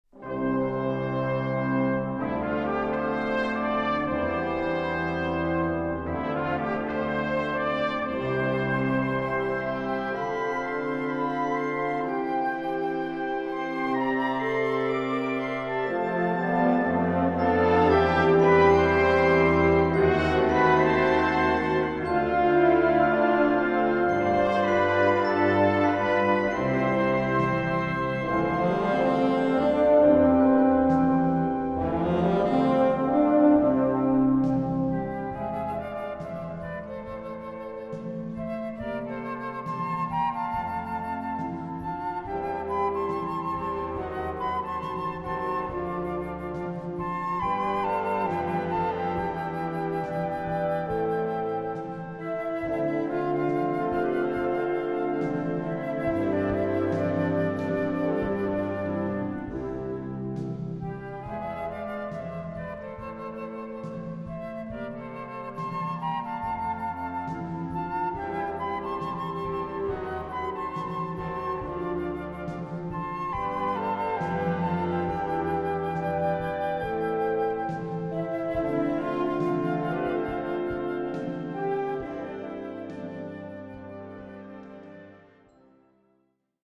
Recueil pour Harmonie/fanfare - Concert Band ou Harmonie